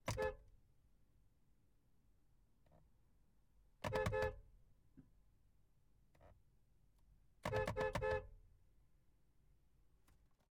Pole Position - Peugeot 307 SW 2004